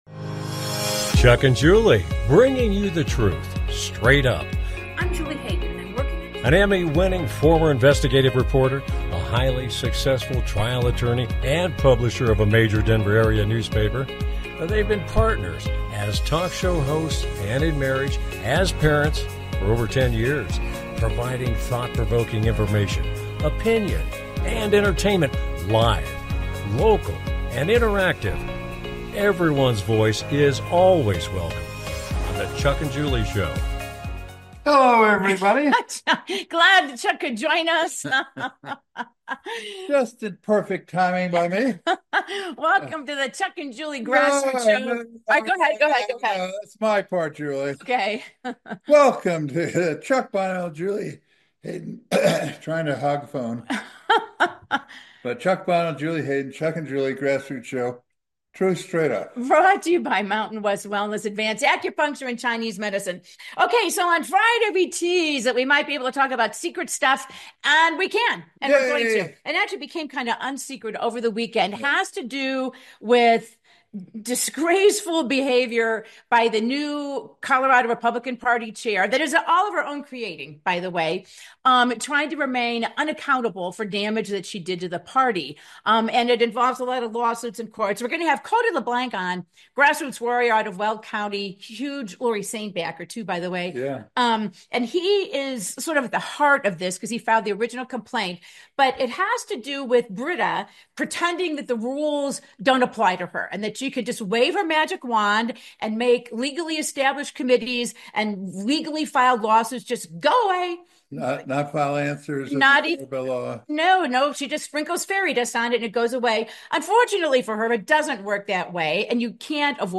Talk Show Episode, Audio Podcast
Their program is a live Internet call-in talk show providing thought provoking information, conversation and entertainment.